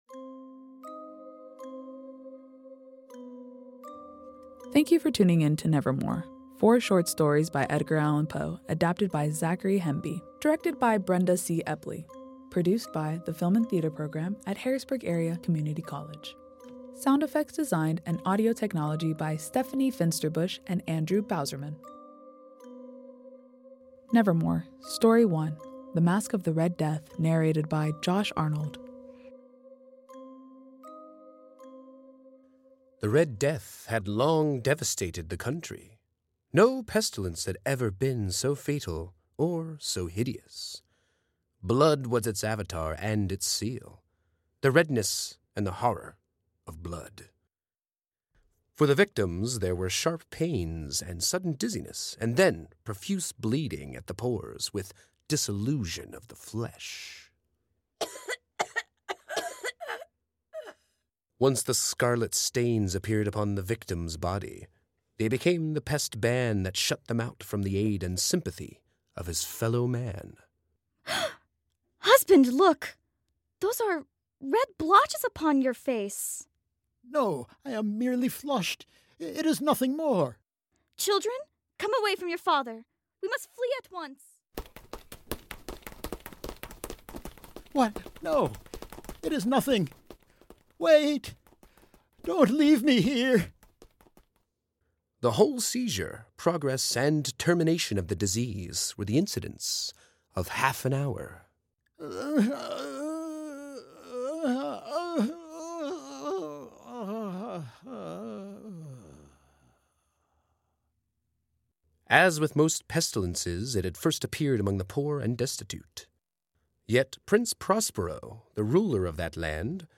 Focusing primarily on classic literature adaptations, the podcast features tales such as The Masque of the Red Death, The Fall of the House of Usher, The Raven, and The Black Cat, with each episode presenting a unique narrative style and immersing the audience in Gothic themes and psychological horror.